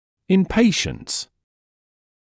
[ɪn ‘peɪʃnts][ин ‘пэйшнтс]у пациентов